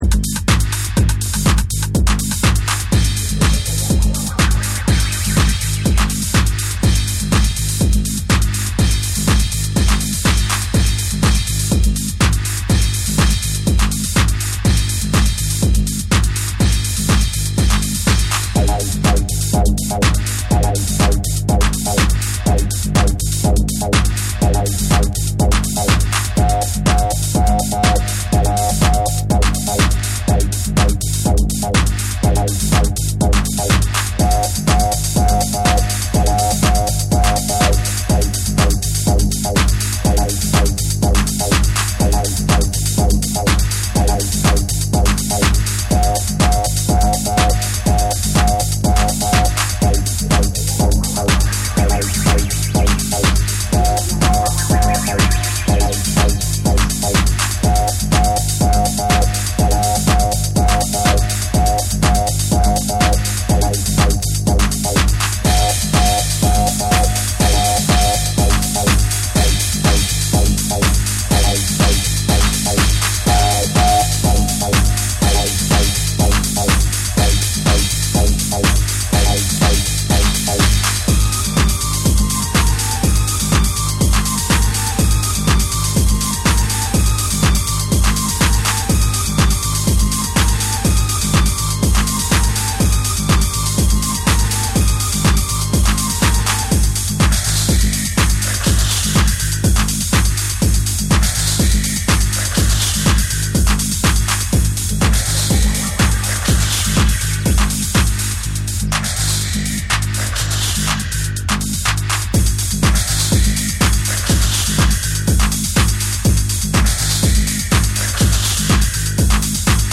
クラブの余韻を感じさせる深みと、ホームリスニングに最適な親密さを兼ね備えた好内容です！
TECHNO & HOUSE / NEW WAVE & ROCK